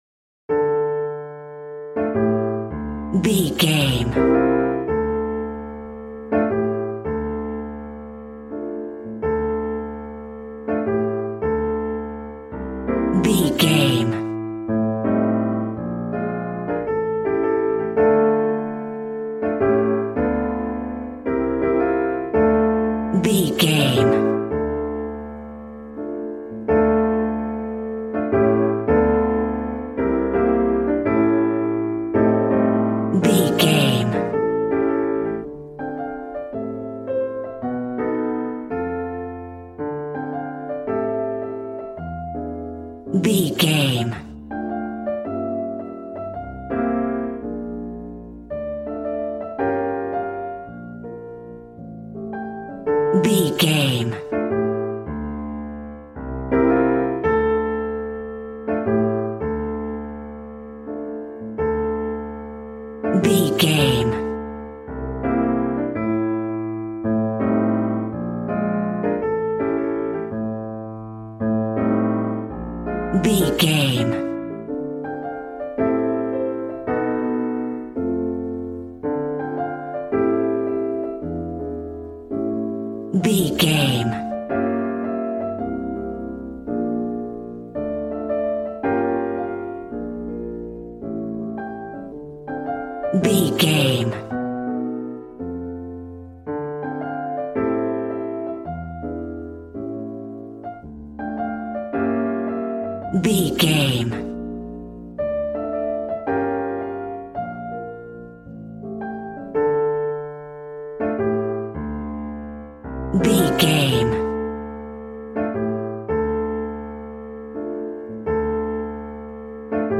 Aeolian/Minor
smooth
piano
drums